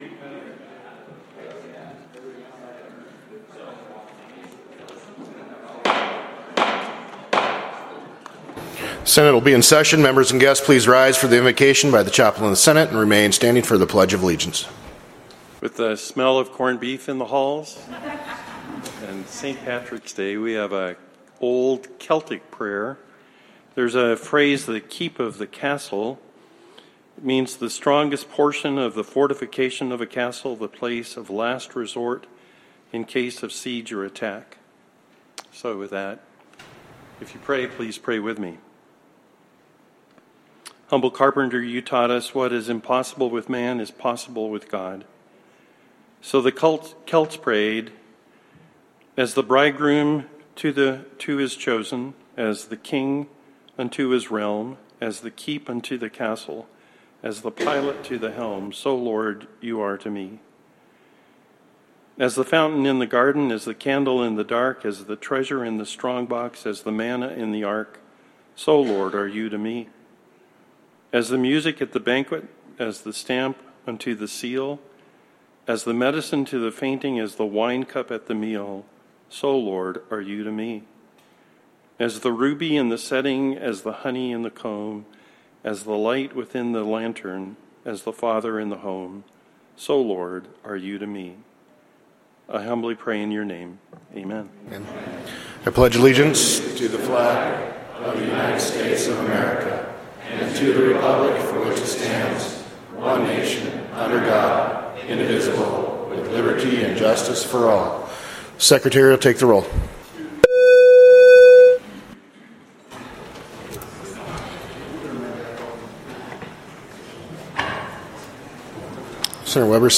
Senate Floor Session